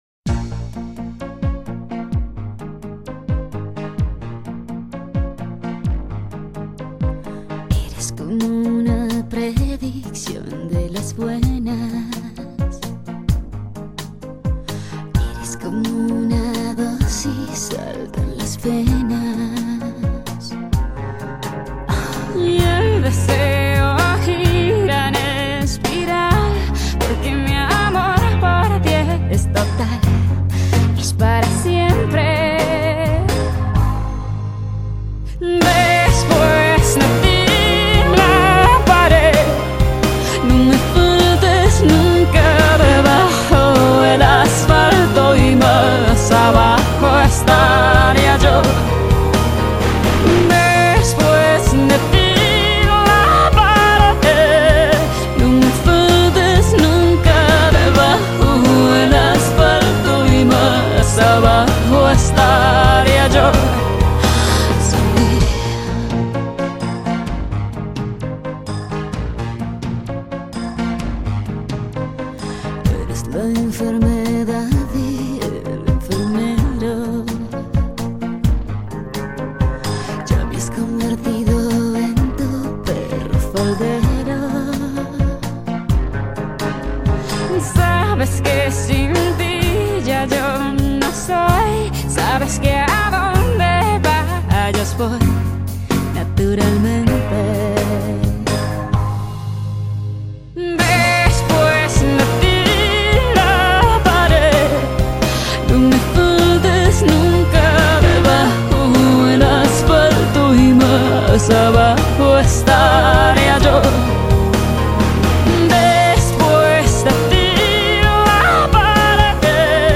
融入了流行、佛拉明哥、摇滚等多种元素